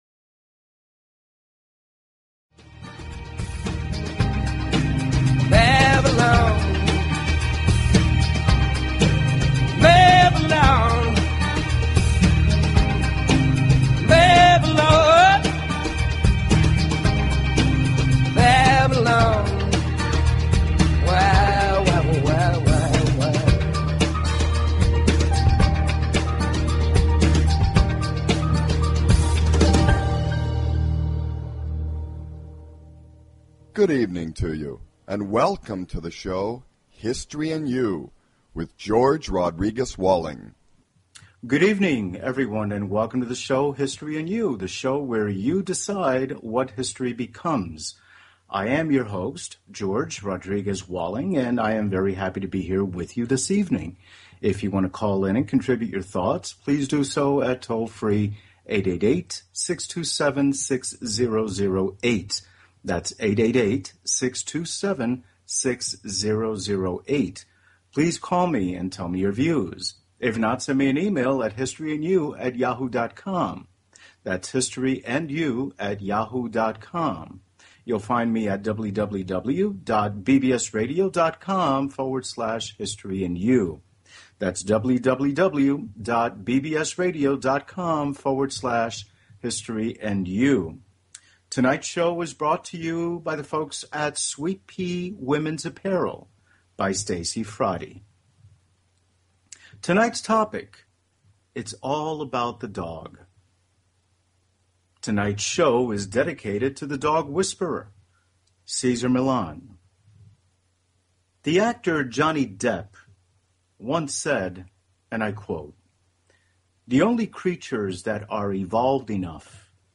Therefore, the variety of topics and history surrounding everyday people will be made current and relevant through insightful discussions.